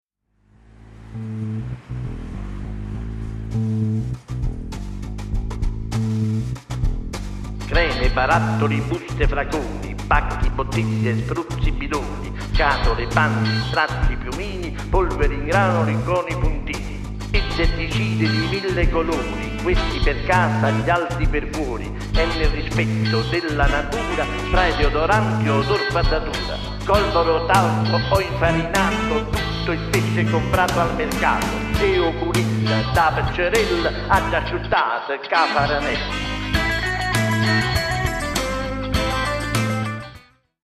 sonorità liriche e orchestrali
ud, darbuka, bandurria...